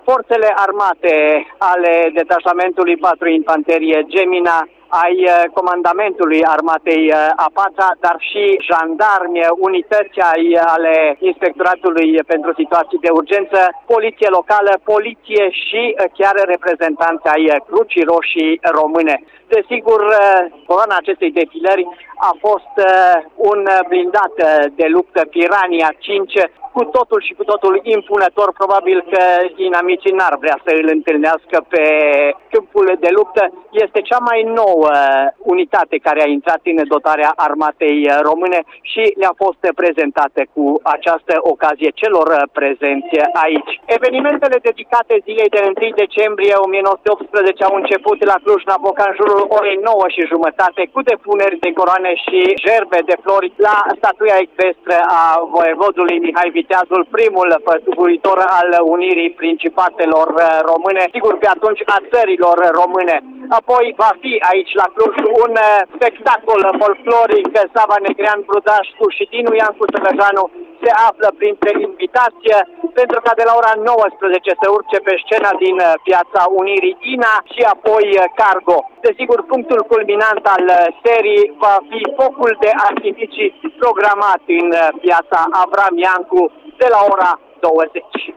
Radio Cluj a fost prezent la parada militară din Piața Avram Iancu: